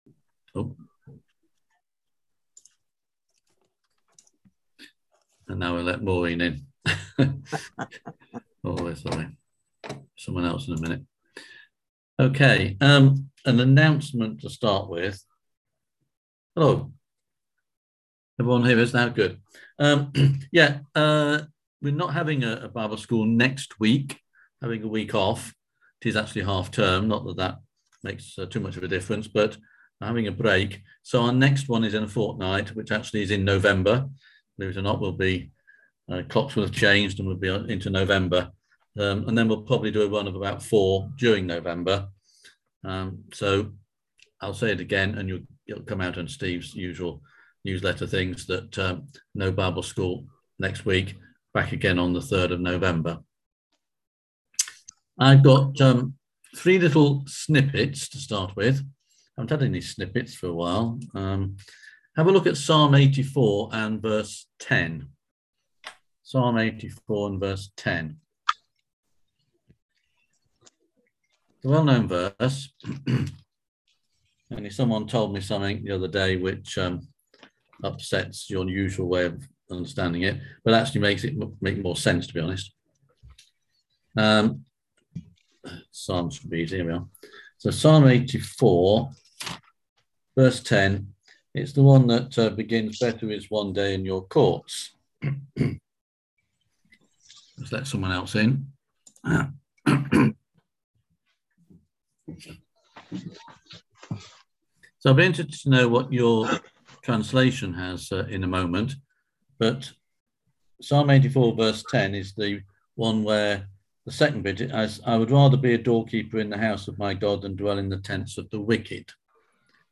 Hebraic Bible school - SALTSHAKERS creativity in action
On October 20th at 7pm – 8:30pm on ZOOM